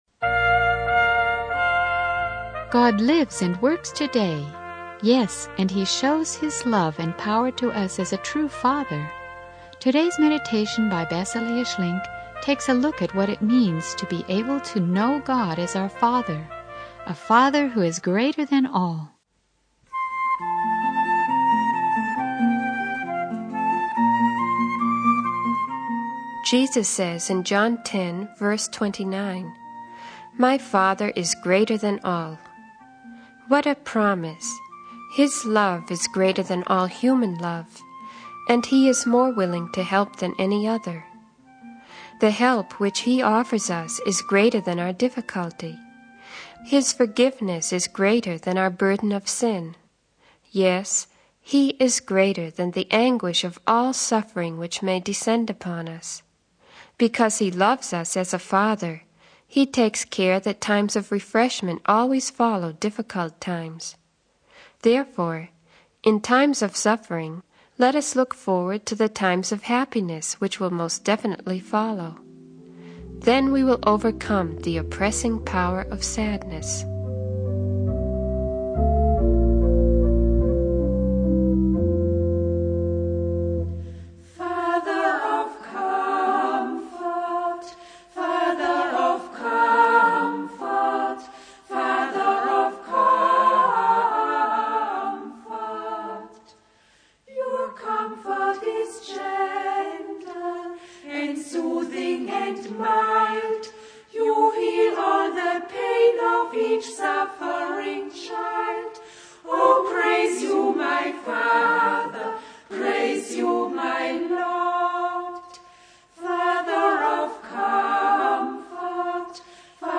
Overall, the sermon emphasizes the power and goodness of God as a true Father.